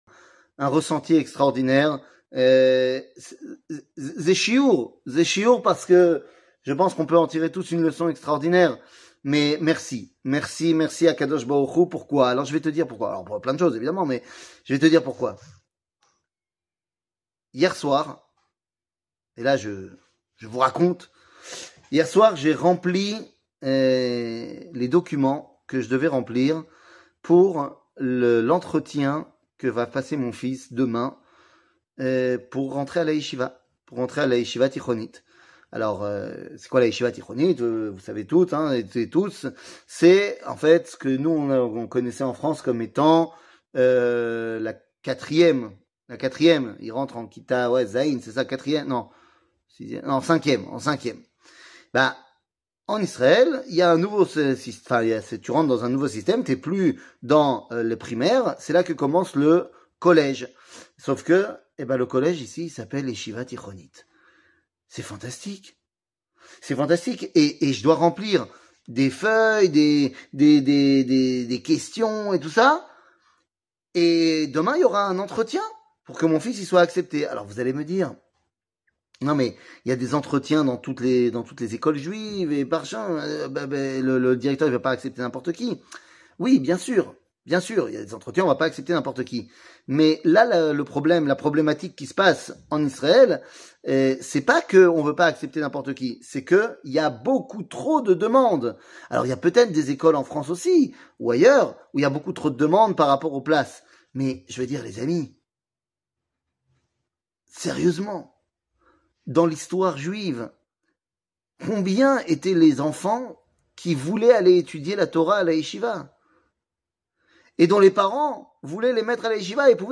קטגוריה Merci Achem 00:05:44 Merci Achem שיעור מ 19 פברואר 2023 05MIN הורדה בקובץ אודיו MP3